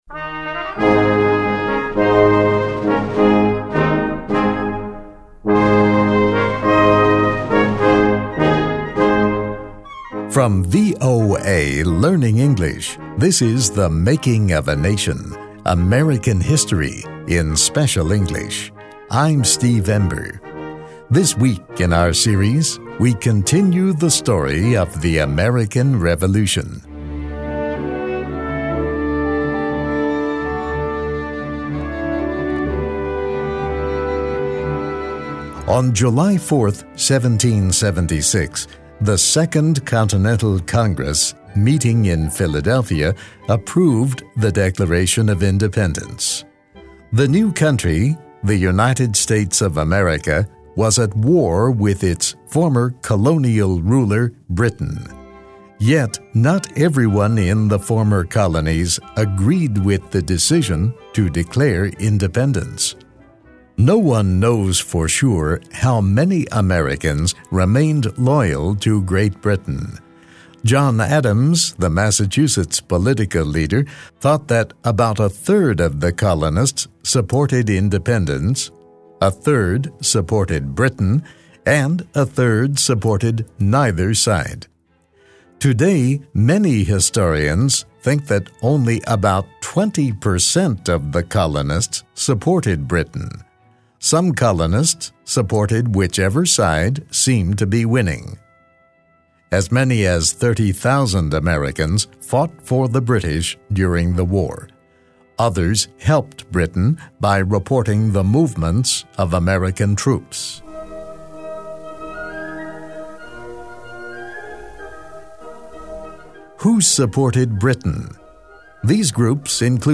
Read, listen and learn English with this story.